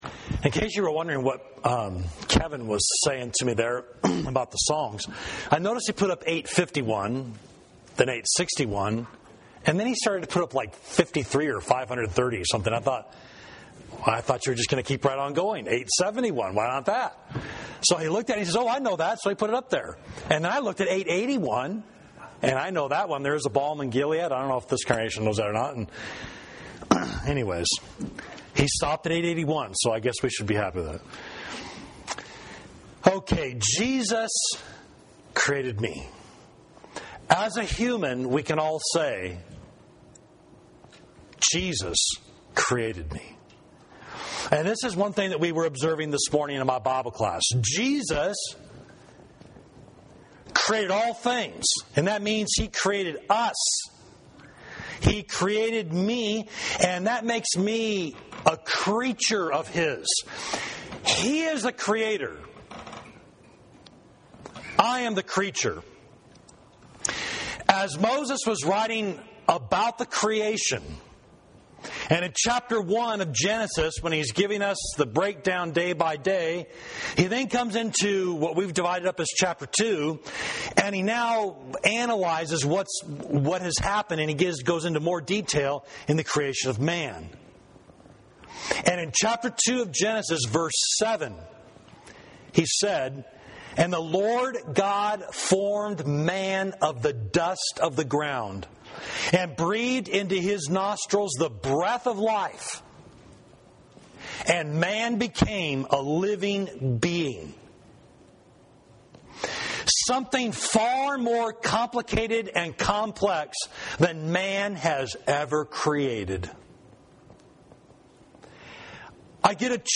Sermon: Jesus and Me